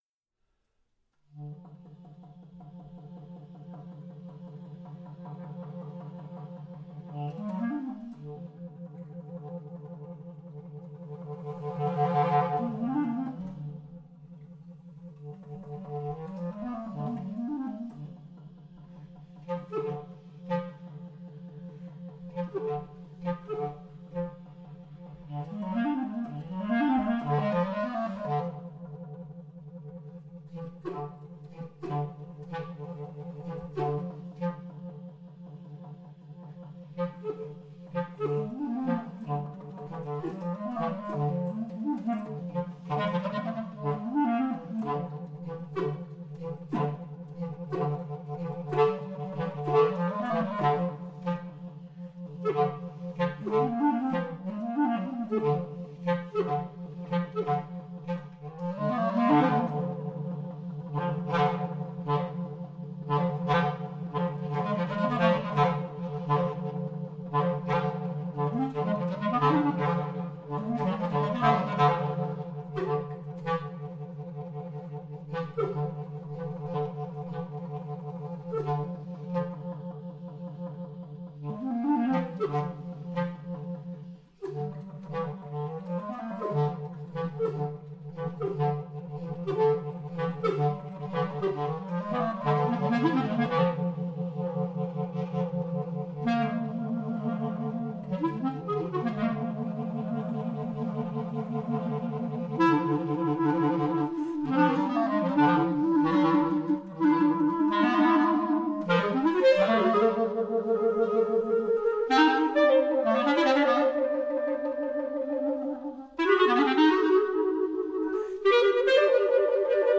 B flat clarinet
BP clarinet